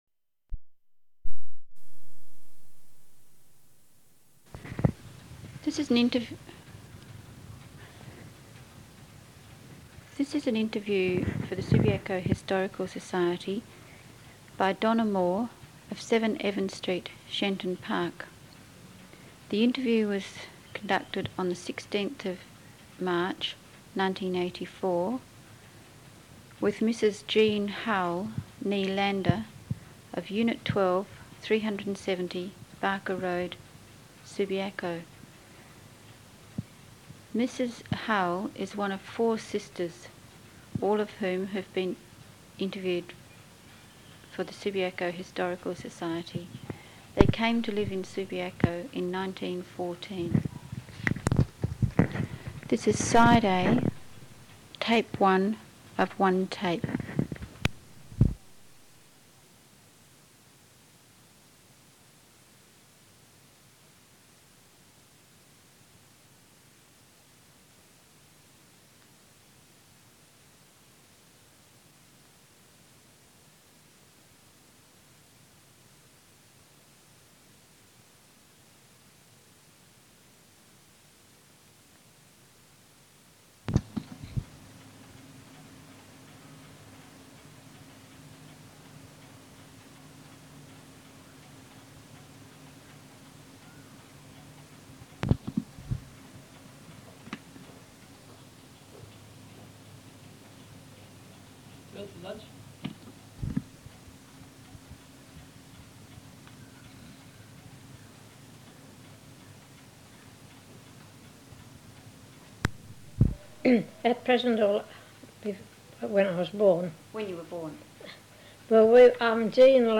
ORAL HISTORY (AUDIO)
Date of Interview: 16 March 1984